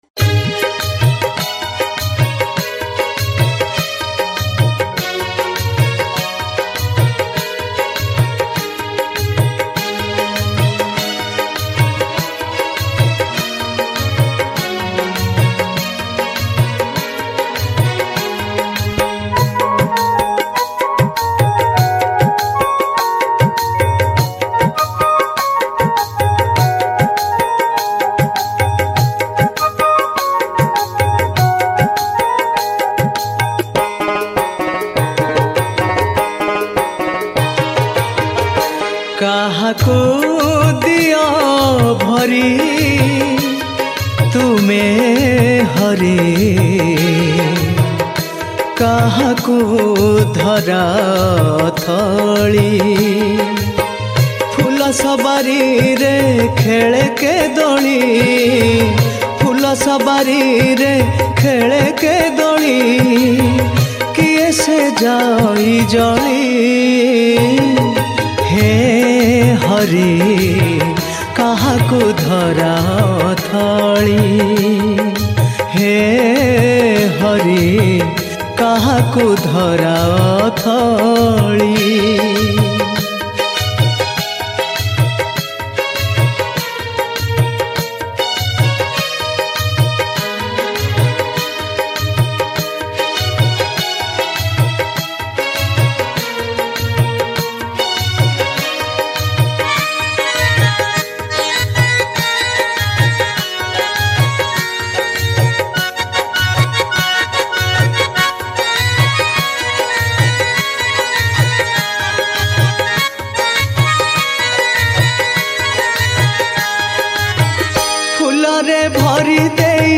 New Odia Album Songs